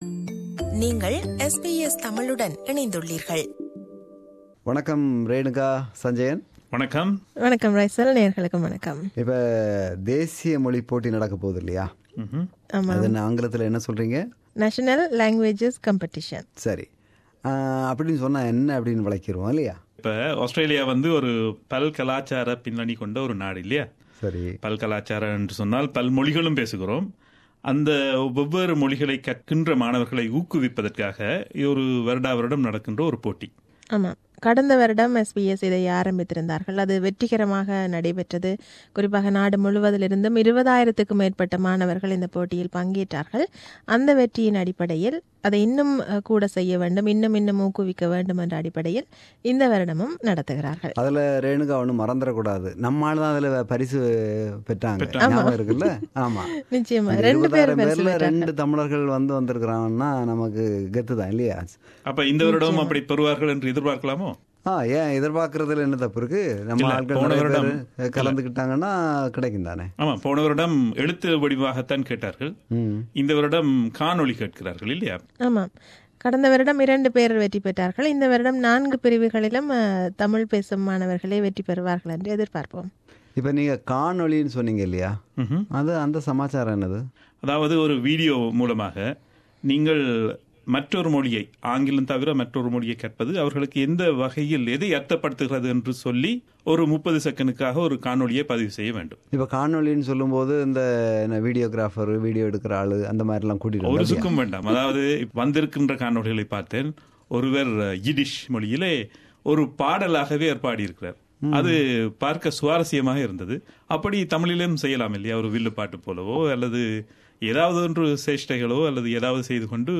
This is a panel discussion on SBS National Languages Competition 2017.